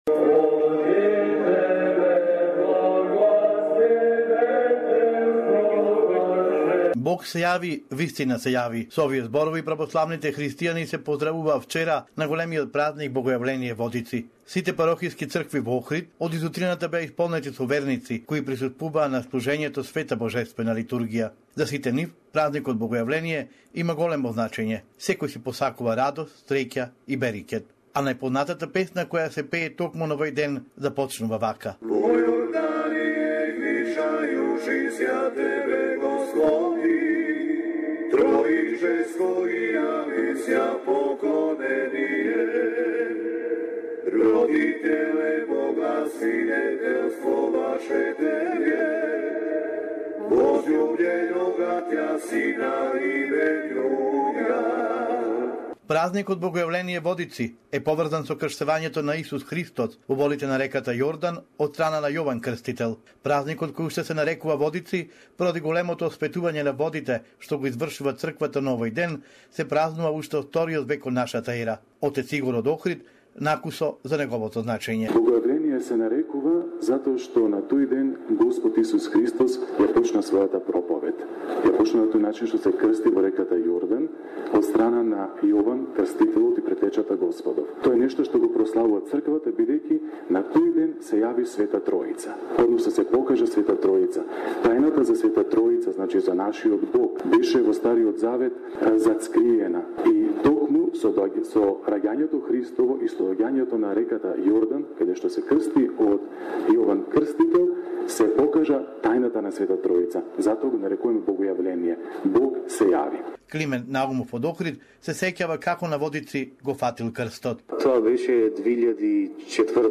special report for SBS Macedonian